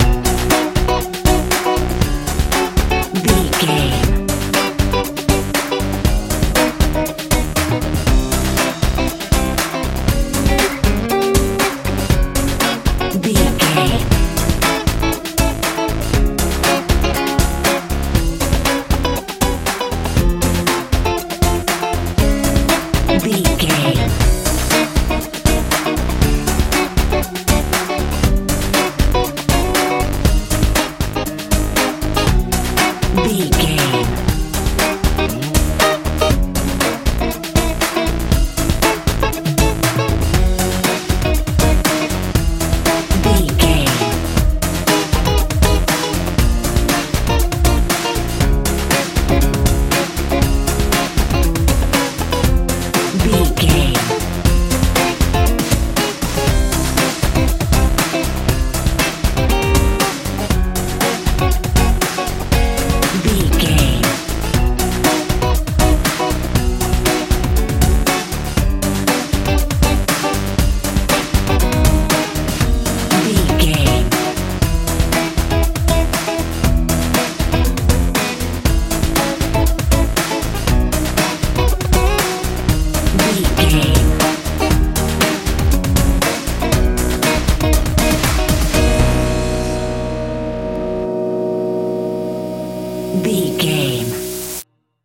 modern dance feel
Ionian/Major
groovy
funky
electric guitar
synthesiser
bass guitar
drums
80s
90s